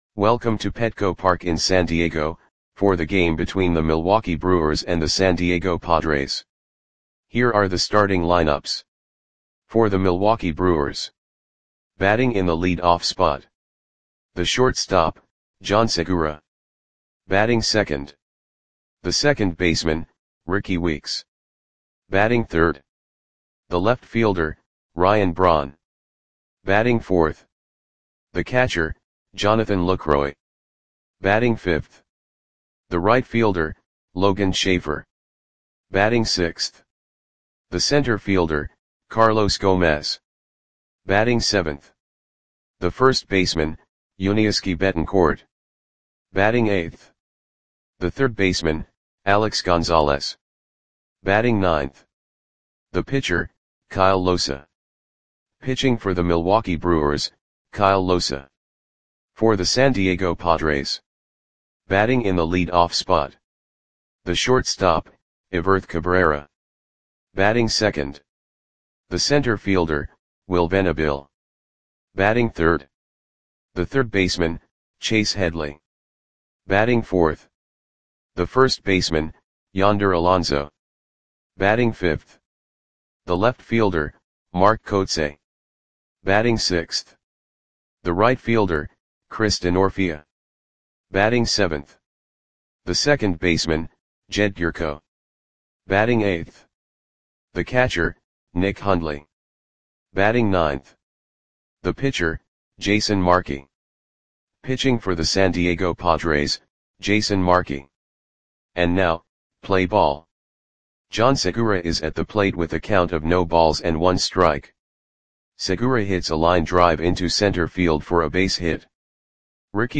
Audio Play-by-Play for San Diego Padres on April 22, 2013